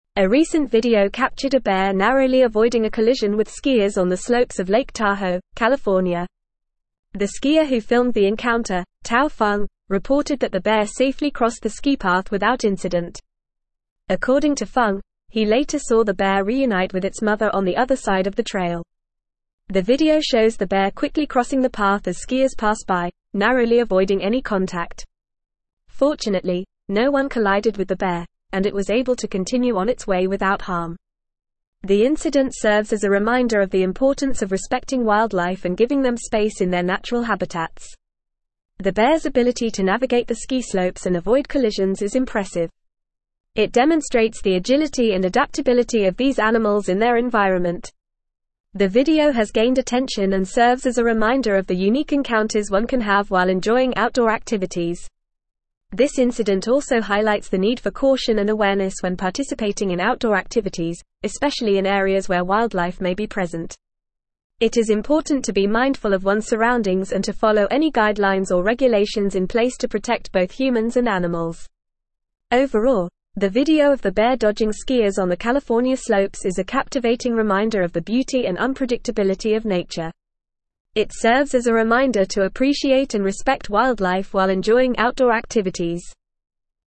Fast
English-Newsroom-Advanced-FAST-Reading-Skier-narrowly-avoids-bear-collision-in-Lake-Tahoe.mp3